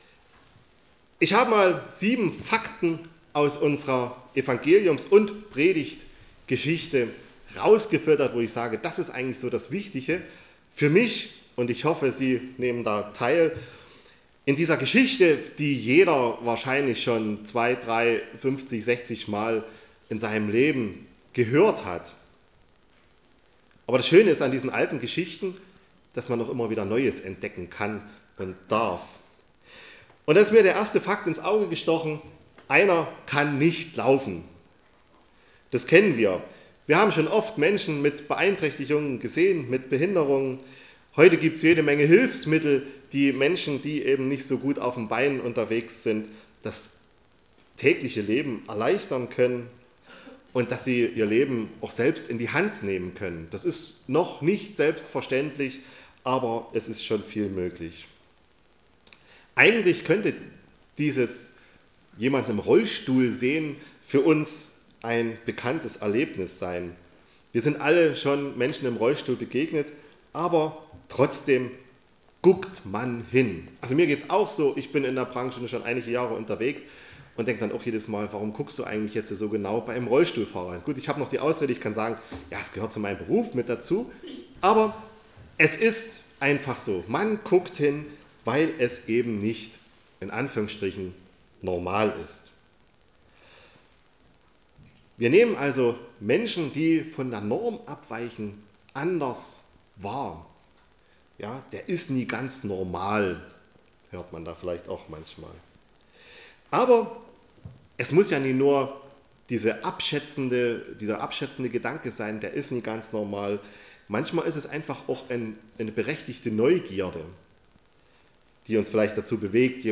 Wir hören den Bibeltext heute in einfacher Sprache.